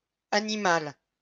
wymowa:
IPA[aniˈmal] ?/i; lm [aniˈmo]